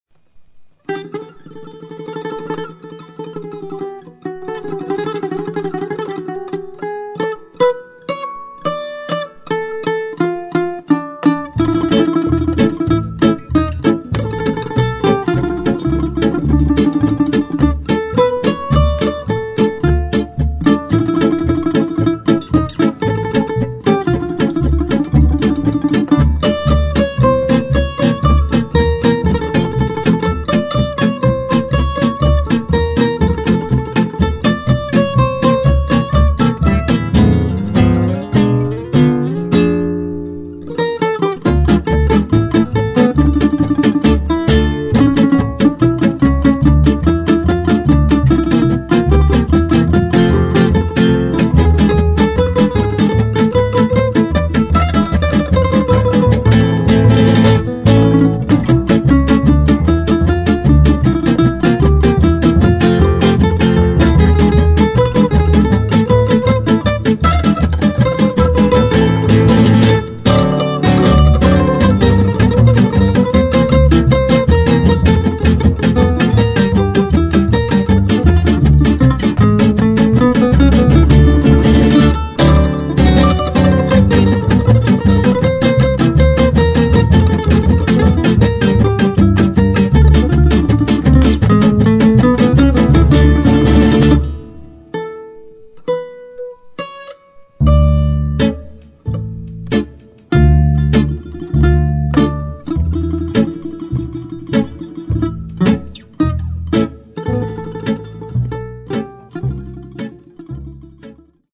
tambura
bajo
guitarra